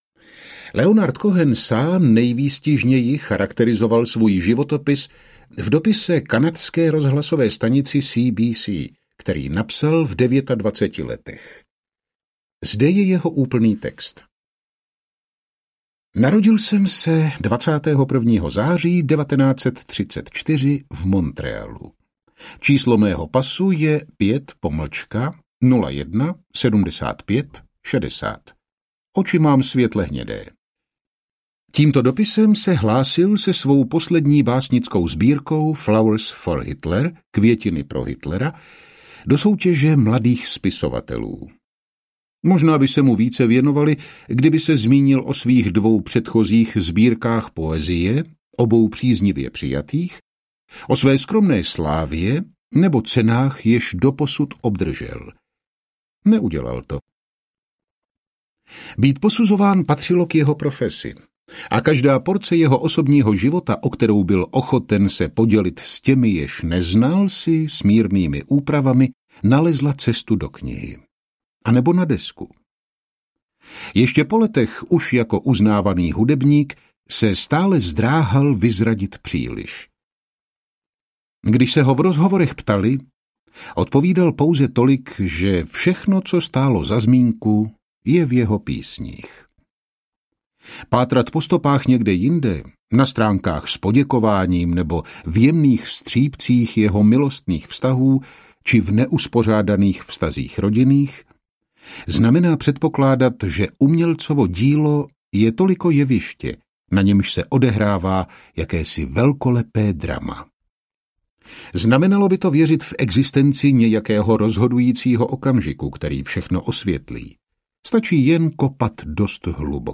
Leonard Cohen - Život, hudba a vykoupení audiokniha
Ukázka z knihy